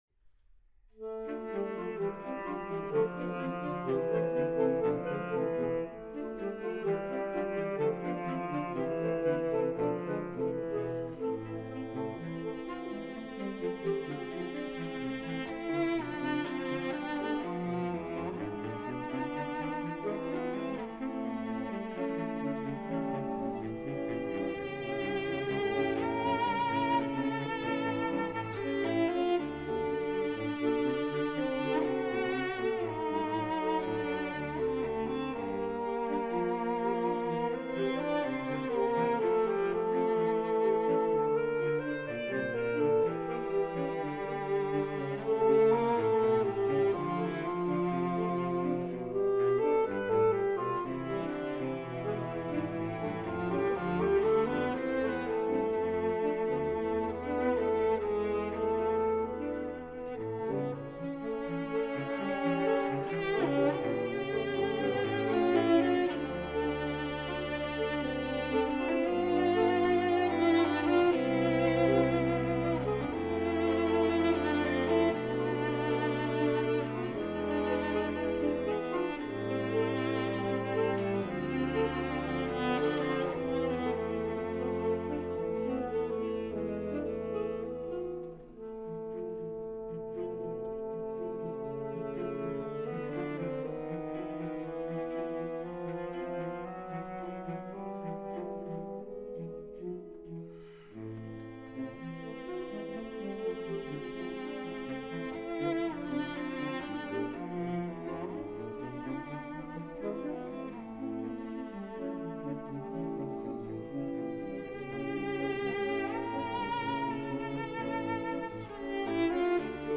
for Cello & Saxophone Quartet